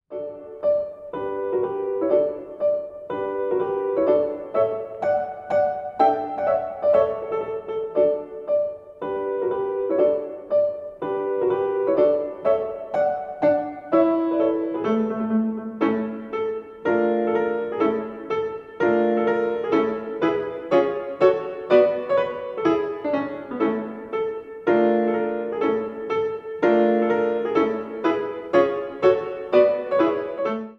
Фрагмент «Детского альбома» (Мажор)